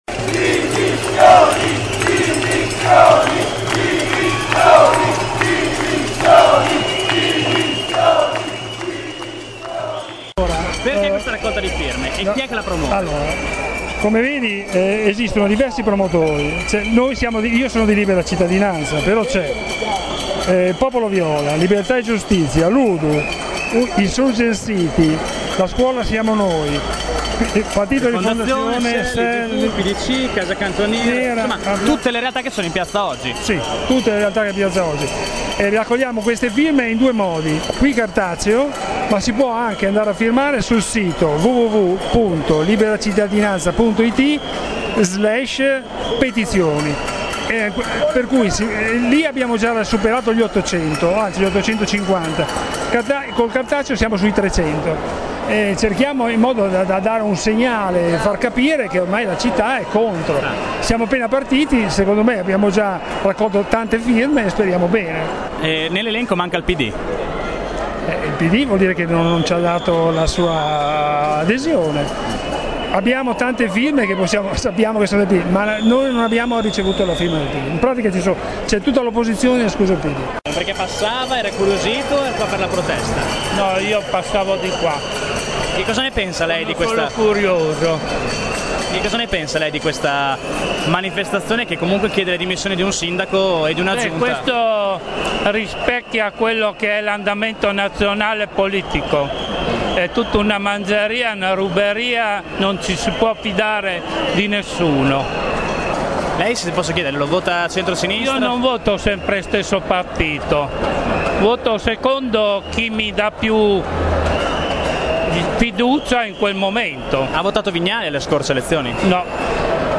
Queste le voci raccolte in piazza
voci_piazza_sito.mp3